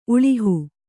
♪ uḷihu